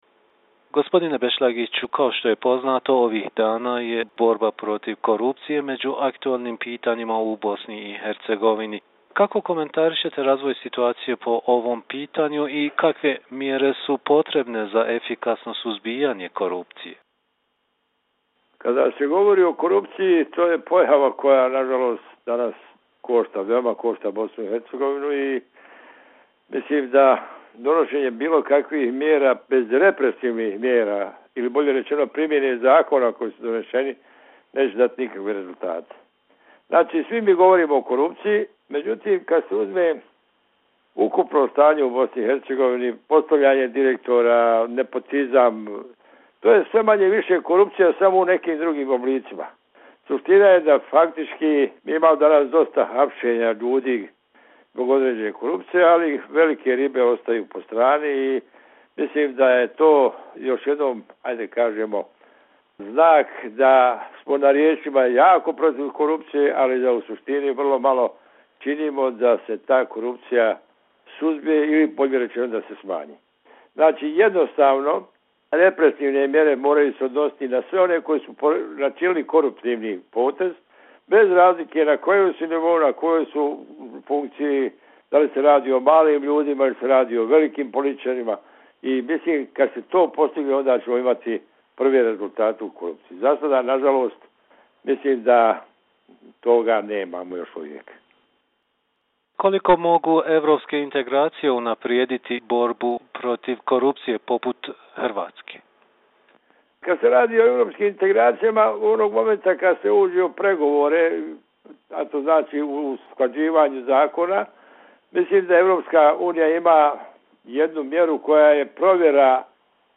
Selim Bešlagić, politički analitičar
Tonske izjave